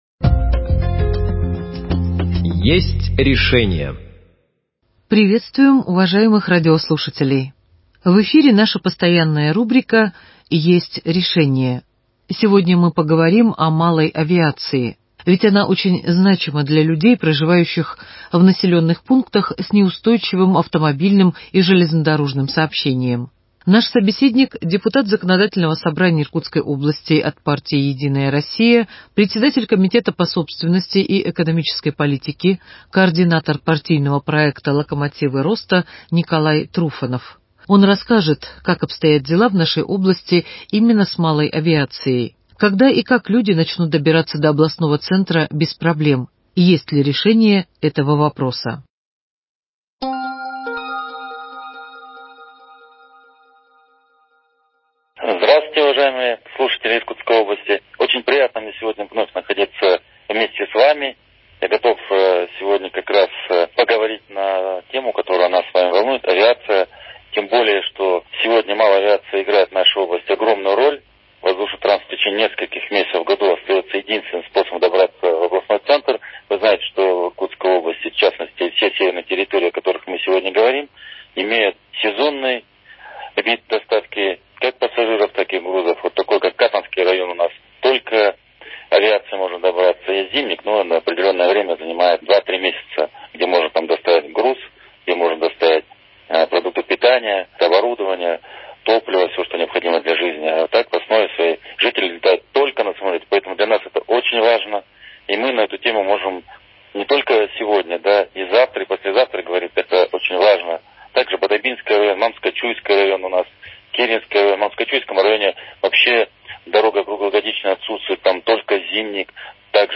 На вопросы о перспективах развития малой авиации в регионе, предприятиях, являющихся опорой экономики нашей области, отвечает депутат Законодательного Собрания Иркутской области от партии «Единая Россия», председатель комитета по собственности и экономической политике, координатор партийного проекта «Локомотивы роста» Николай Труфанов.